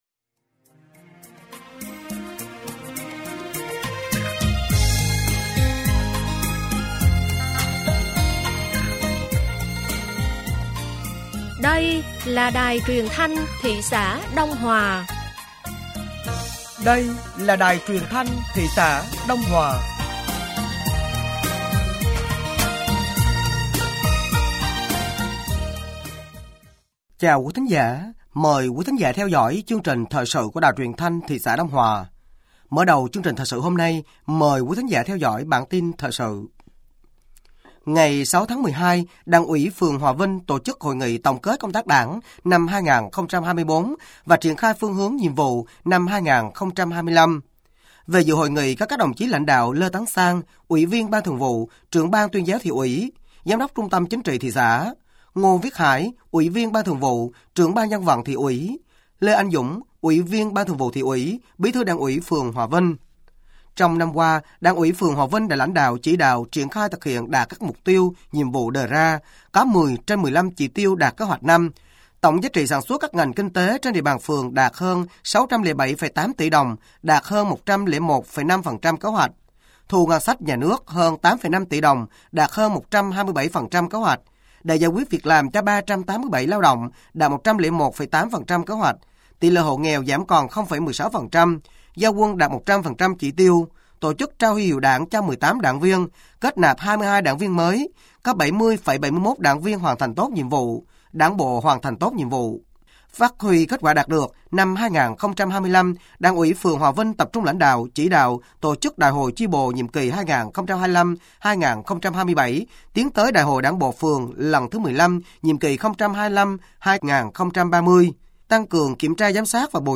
Thời sự tối ngày 07 và sáng ngày 08 tháng 12 năm 2024